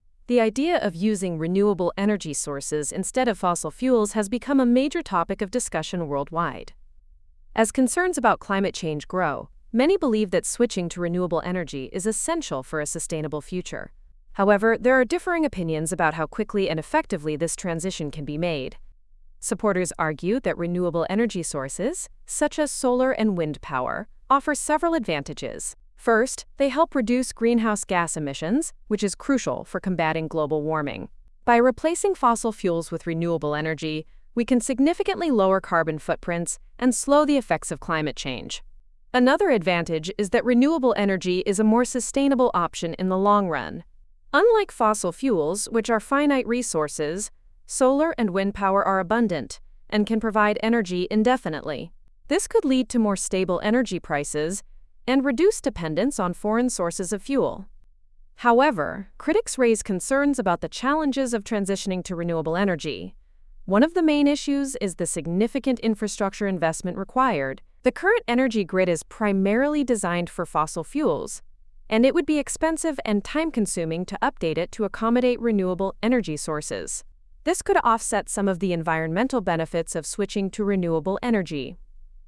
読み上げはこちら：